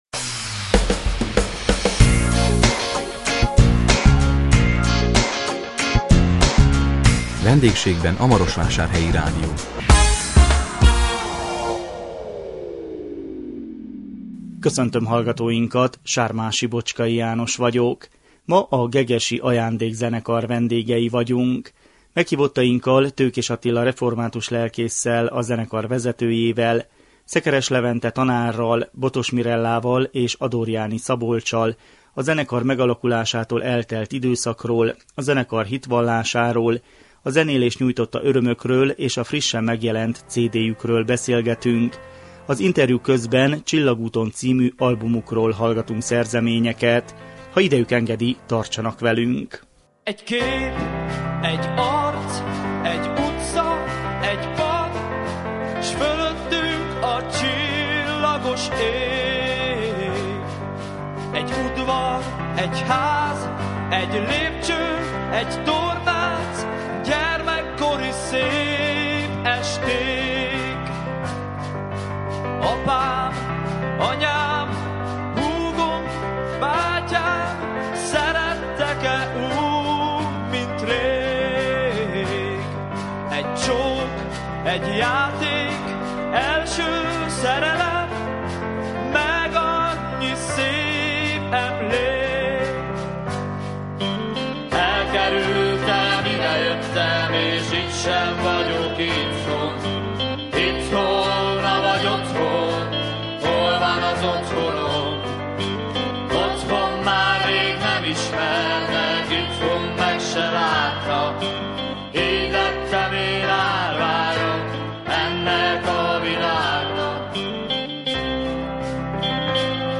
A 2015 december 10-i műsorunkban a gegesi Ajándék Zenekar vendégei voltunk.